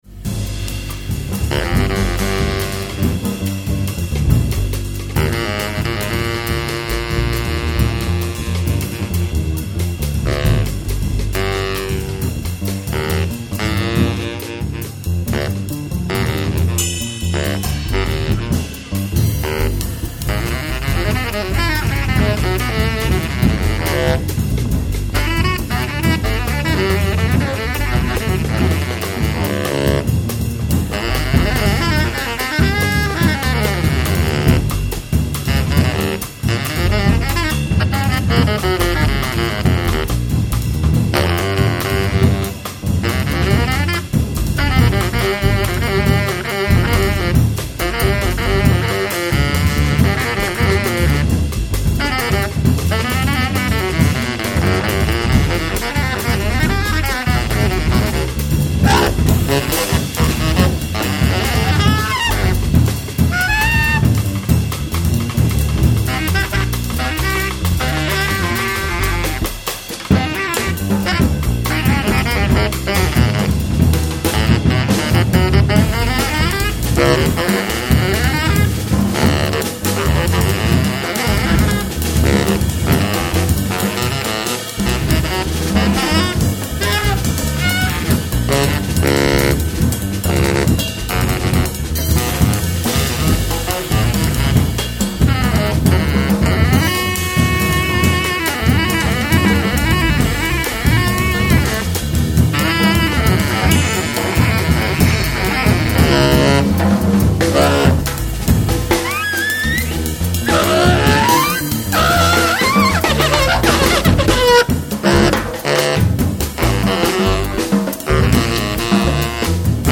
saxophones
Recorded in concert at Glenn Miller Café,
Stockholm, Sweden, on September 4, 2001.